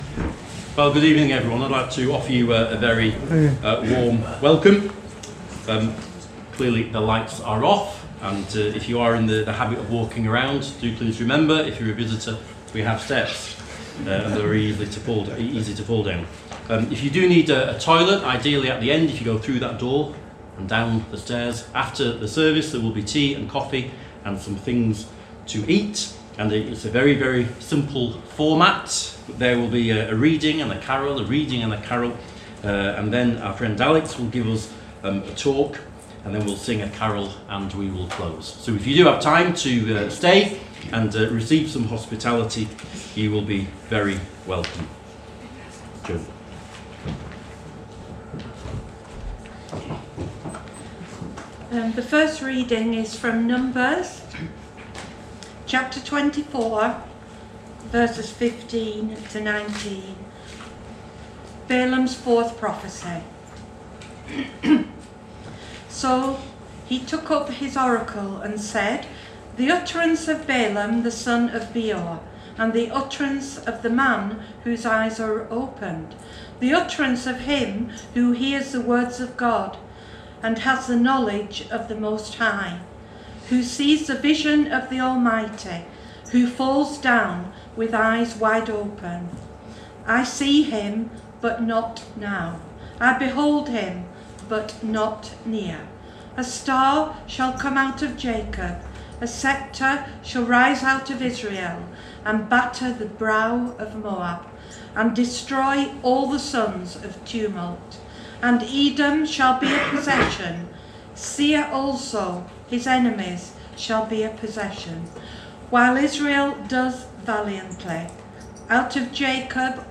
Below is audio of the full service.
2025-12-21 Carol By Candlelight Service If you listen to the whole service on here (as opposed to just the sermon), would you let us know?